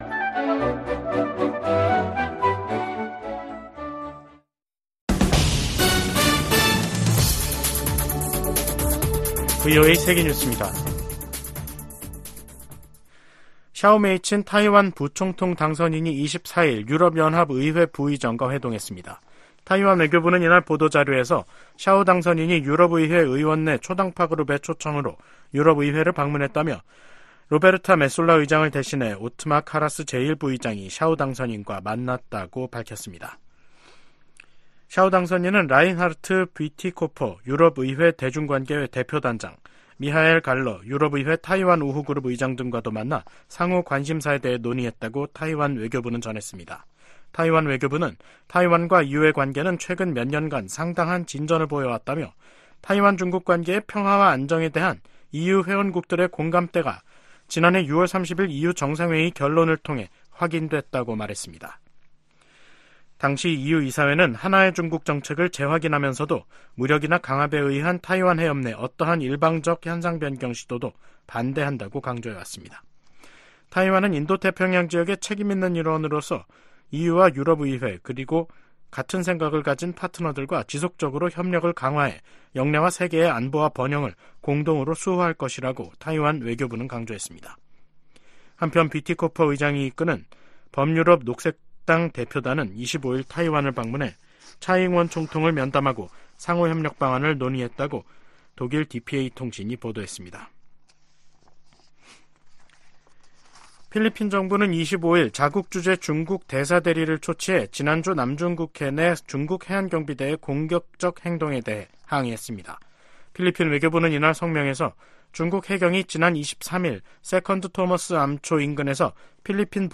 VOA 한국어 간판 뉴스 프로그램 '뉴스 투데이', 2024년 3월 25일 3부 방송입니다. 러시아가 우크라이나를 향해 최소 10차례에 걸쳐 북한제 탄도미사일 40여 발을 발사했다고 로버트 우드 유엔주재 미국 차석대사가 밝혔습니다. 김여정 북한 노동당 부부장은 기시다 후미오 일본 총리로부터 정상회담 제의를 받았다고 밝혔습니다. 미 국무부는 북한에 고문 증거 없다는 중국 주장을 일축하고, 고문방지협약에 따른 송환 금지 의무 준수를 촉구했습니다.